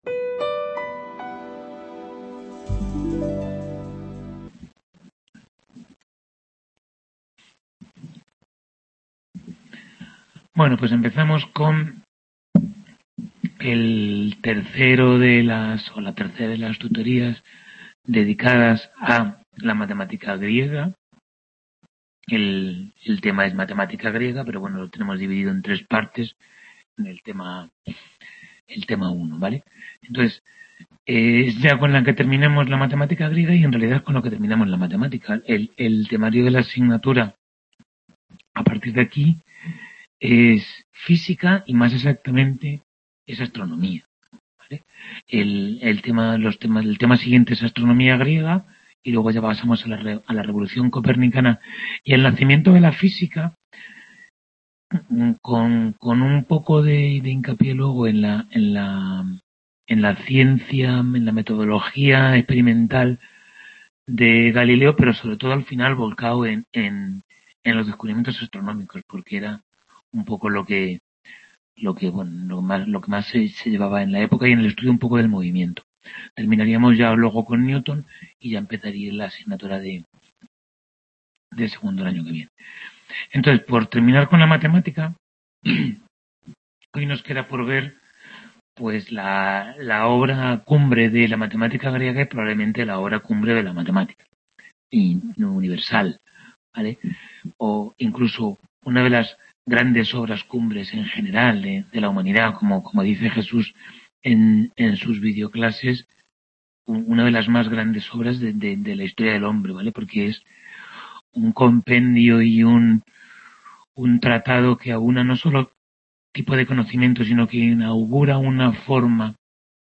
Tutoría 3 de Historia General de la Ciencia I | Repositorio Digital